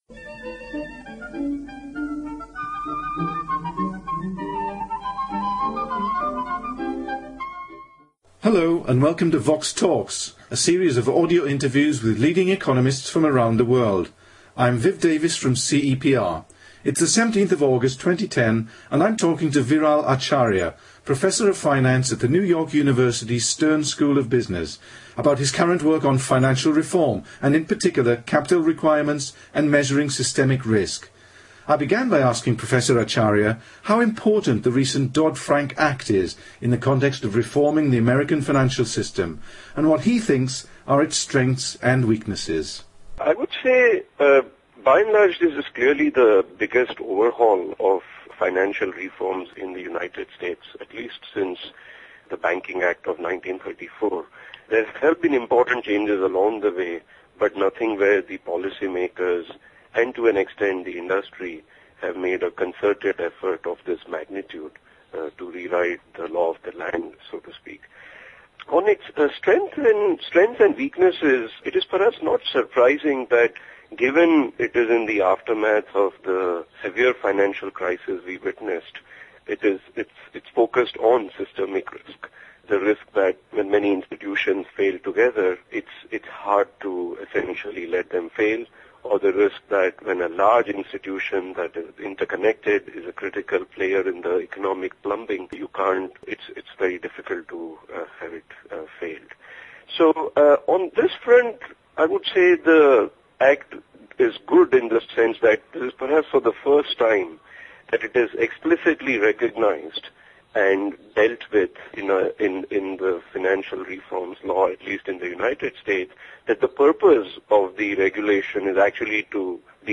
Viral Acharya interviewed